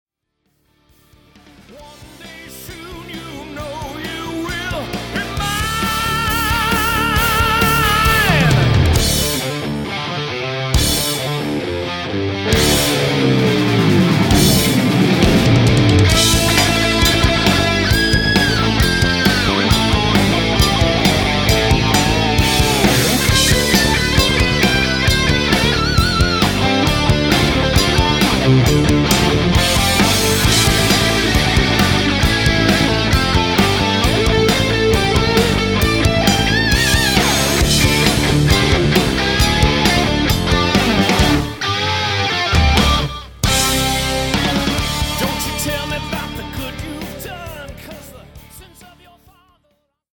Here's a snippet of the guitar solo with Detune on it. Forgive the mix... it's just a rough for tracking.
FYI, lead guitar is a Dean Razorback > Marshall 1979 JMP 100-watt > Vintage 30s 4x12 > Groove Tubes GT-66 > Millennia HV-3C > HD192.
Oh yeah... and the amp is set to "unGodly Loud!" :shock:
GDTD_Gtr_Solo.mp3